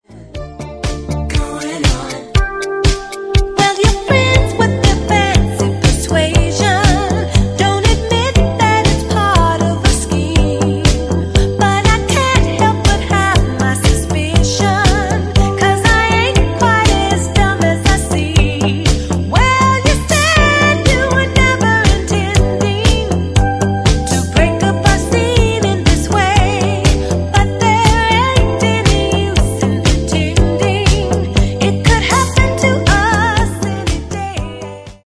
Genere:   Pop | Rock | Dance